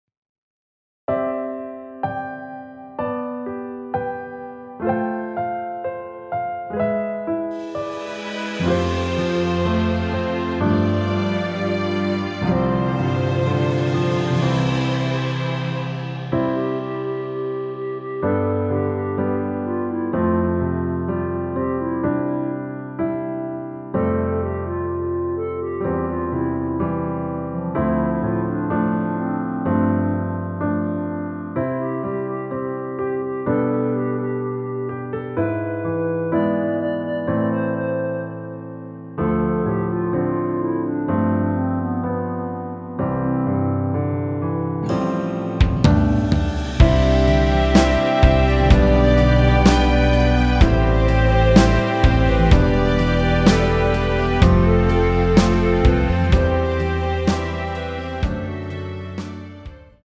원키에서(-1)내린? (1절앞+후렴)으로 진행되는 멜로디 포함된 MR입니다.(미리듣기 확인)
◈ 곡명 옆 (-1)은 반음 내림, (+1)은 반음 올림 입니다.
앞부분30초, 뒷부분30초씩 편집해서 올려 드리고 있습니다.